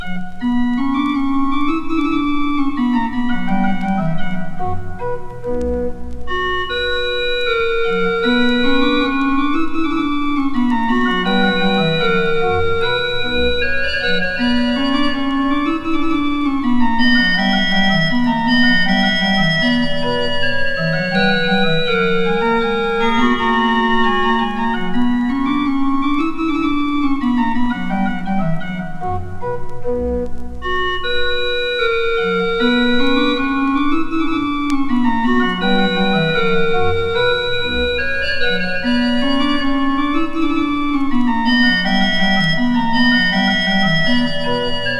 Classical, Baroque　France　12inchレコード　33rpm　Mono